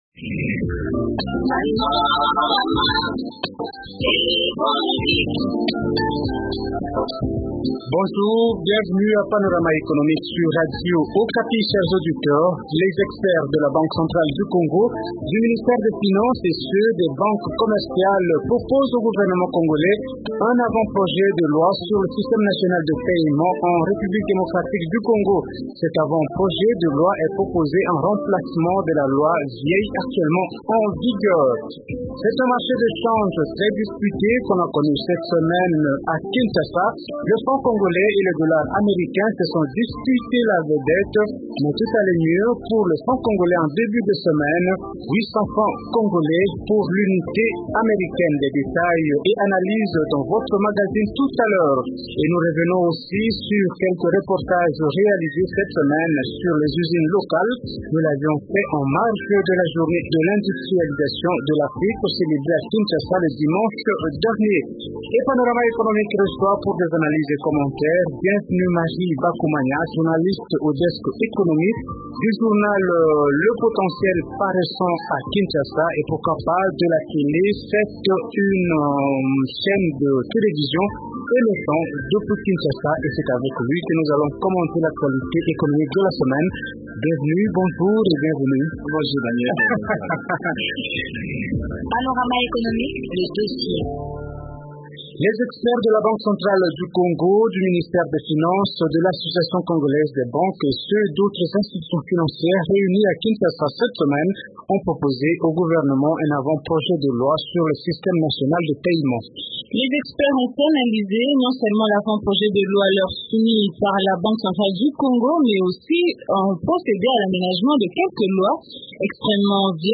En marge de la célébration de la journée de l’industrialisation de l’Afrique, Panorama économique a réalisé un reportage à l’usine locale de fabrication de la liqueur “Pousse café”.